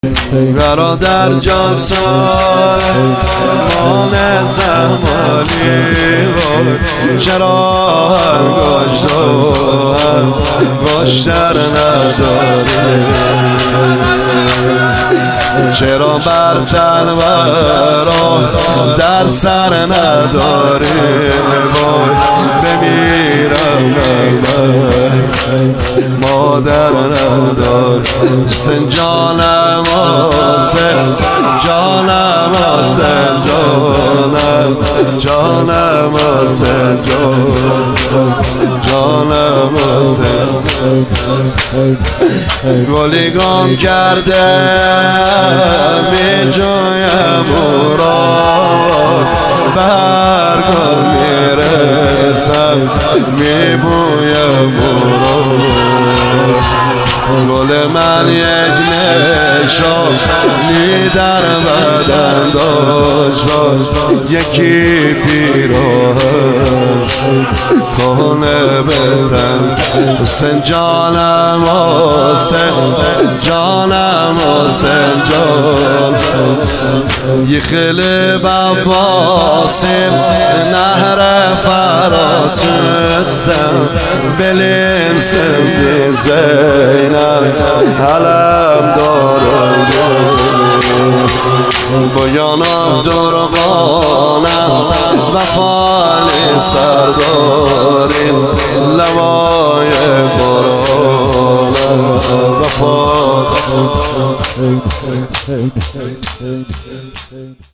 شهادت حضرت زینب سلام الله علیها 1399